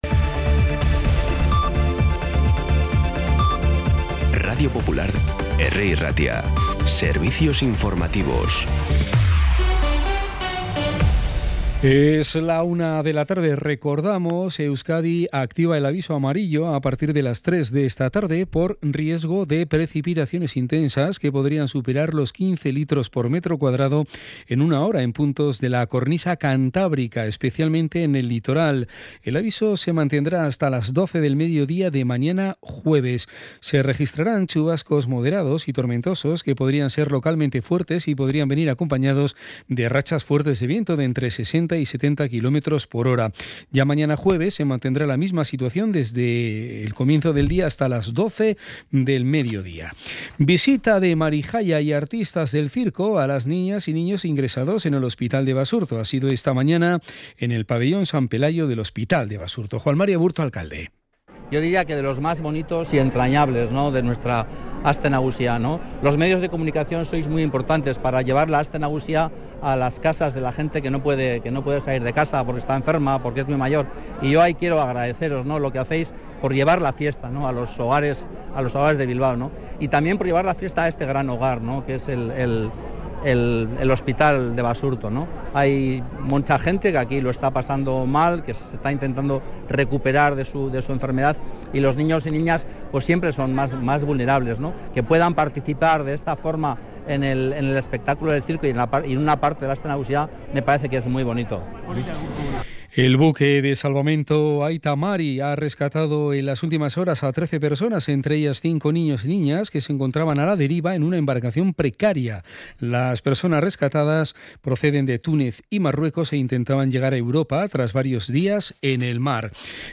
Las noticias de Bilbao y Bizkaia del 20 de agosto a las 13
Los titulares actualizados con las voces del día. Bilbao, Bizkaia, comarcas, política, sociedad, cultura, sucesos, información de servicio público.